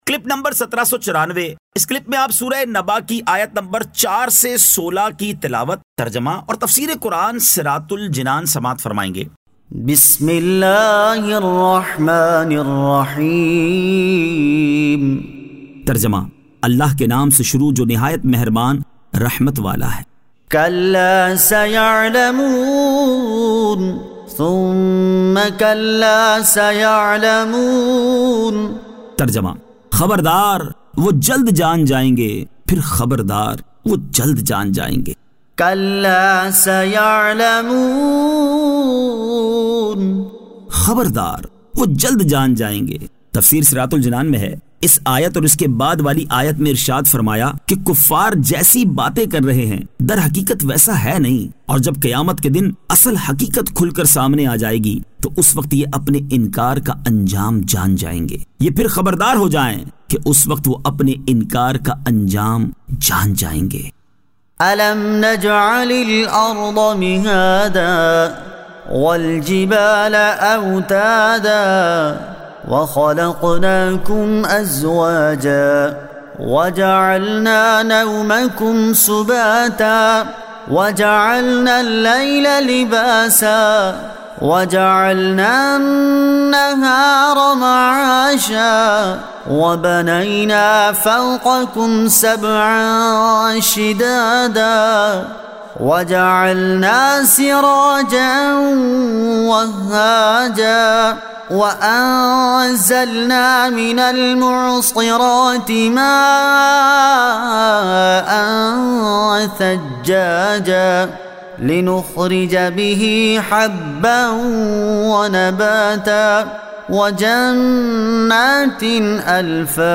Surah An-Naba 04 To 16 Tilawat , Tarjama , Tafseer